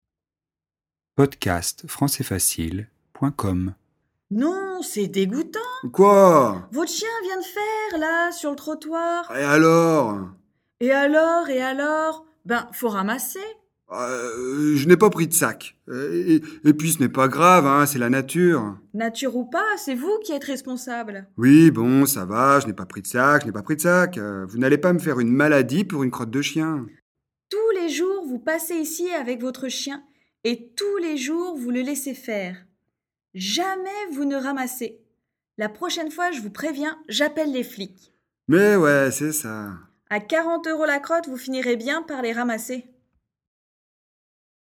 Dialogue FLE (français familier), niveau intermédiaire (B1) sur le thème de la vie quotidienne.